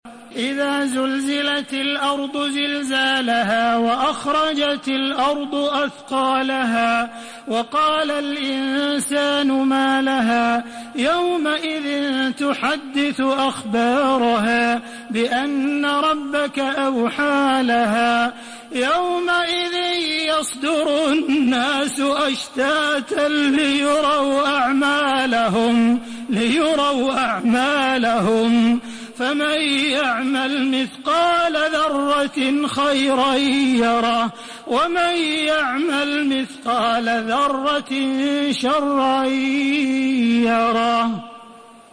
Makkah Taraweeh 1432
Murattal